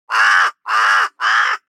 دانلود آهنگ پرنده 13 از افکت صوتی انسان و موجودات زنده
دانلود صدای پرنده 13 از ساعد نیوز با لینک مستقیم و کیفیت بالا
جلوه های صوتی